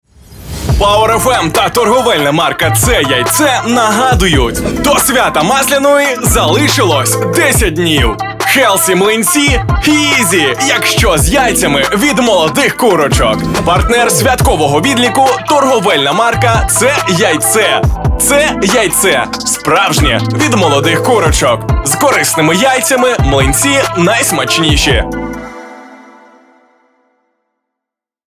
Также для запуска рекламной кампании на радио было записано 5 разных радио роликов для 5-ти радиостанций с уникальной и эмоциональной подачей, задорным и привлекательным текстом (слушайте ниже!).